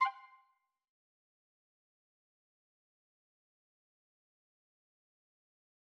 obsydianx-interface-sfx-pack-1
back_style_4_001.wav